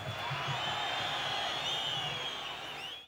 crowdnoise.wav